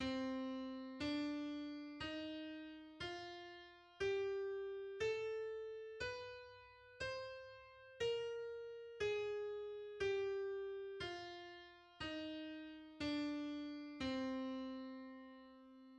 The C natural minor scale is:
The C harmonic minor and melodic minor scales are: